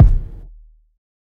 HFMKick6.wav